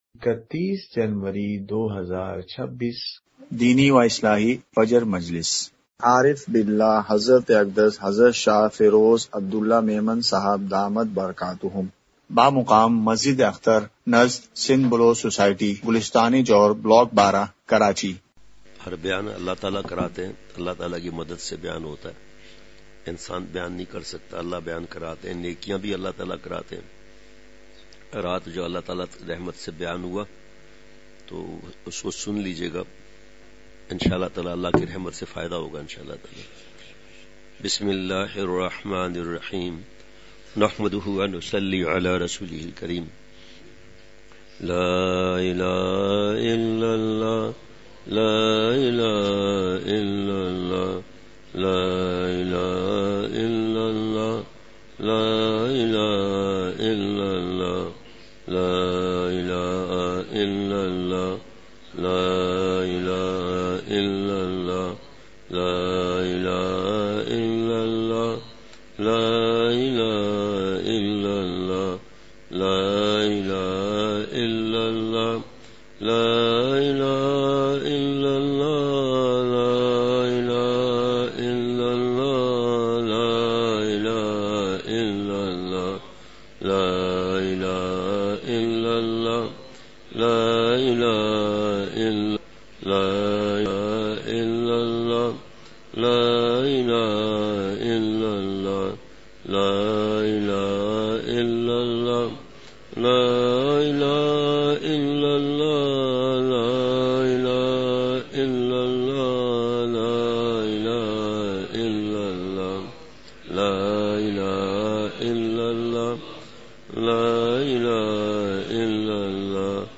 *مقام:مسجد اختر نزد سندھ بلوچ سوسائٹی گلستانِ جوہر کراچی*